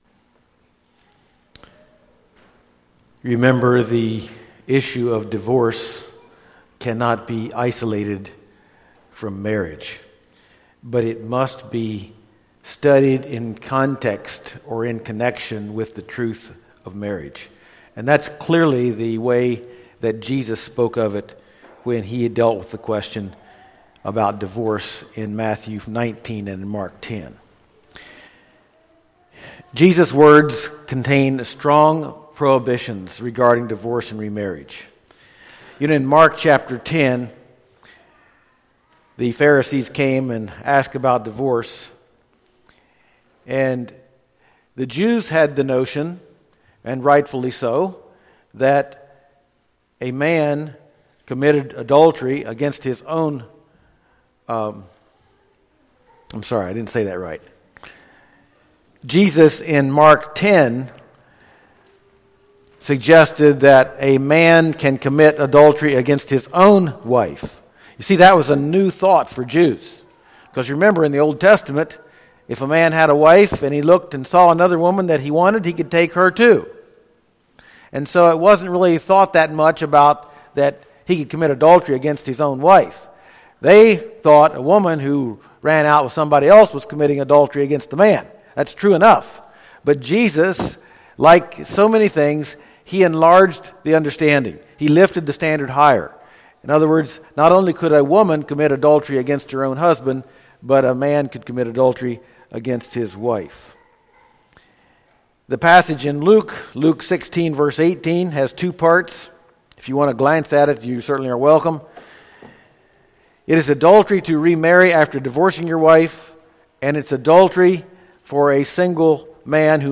Series: Marriage / Divorce / Remarriage Service Type: Sunday Morning %todo_render% « Foundations of Marriage Teachings of Paul